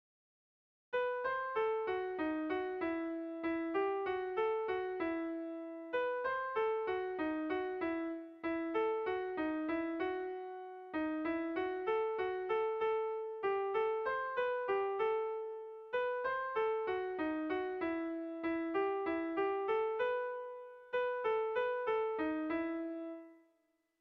Gabonetakoa
Zortziko txikia (hg) / Lau puntuko txikia (ip)
A1A2BA3